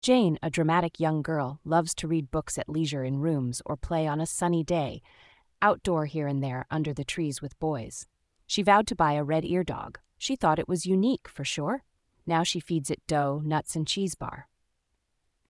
phonetic-pangram-nova.mp3